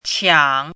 “抢”读音
qiǎng
qiǎng.mp3